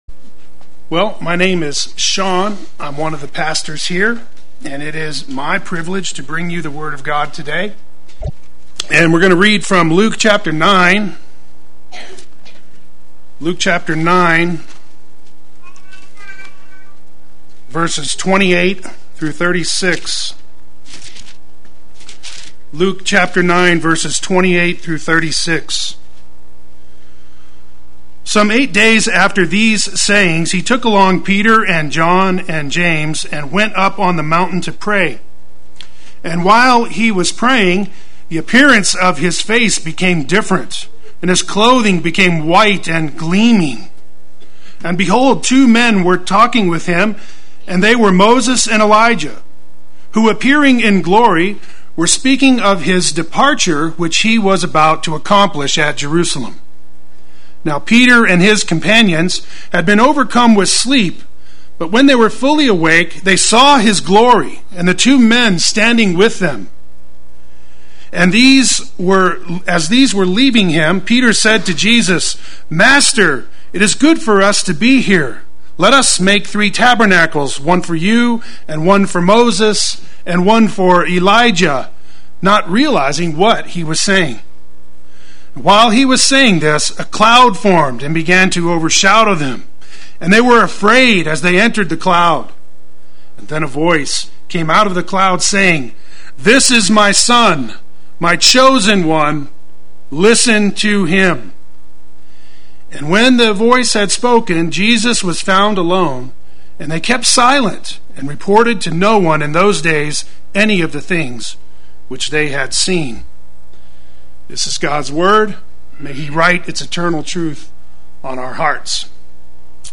Play Sermon Get HCF Teaching Automatically.
The Transfiguration Sunday Worship